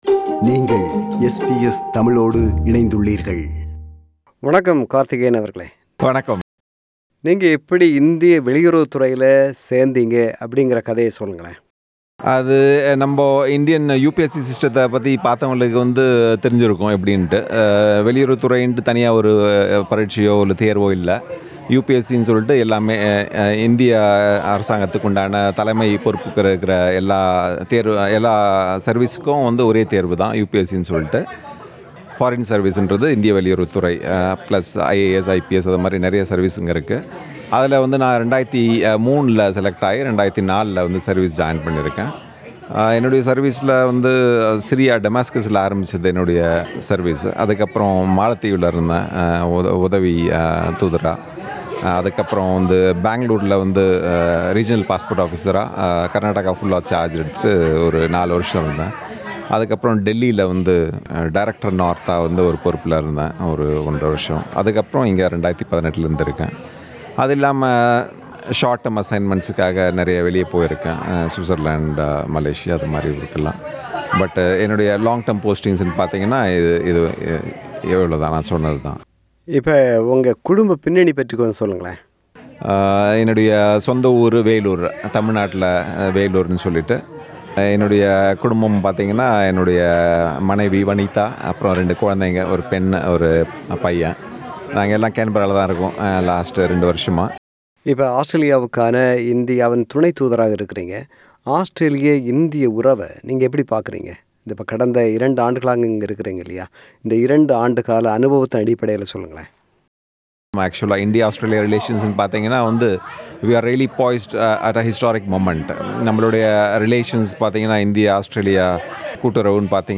Interview with Indian Deputy High Commissioner to Australia